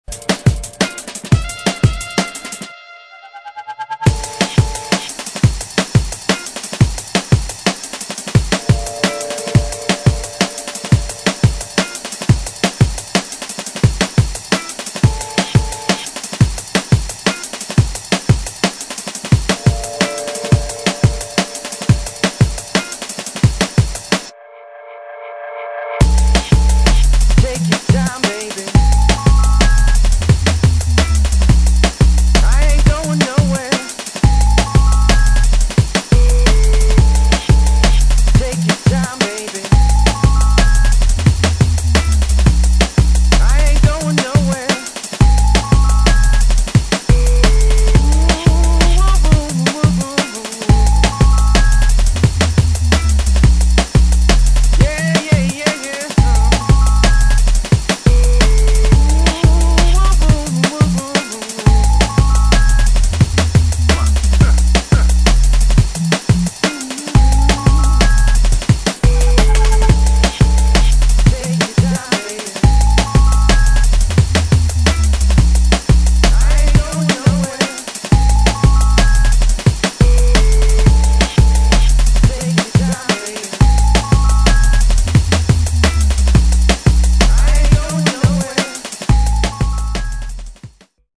[ DRUM'N'BASS / JUNGLE ]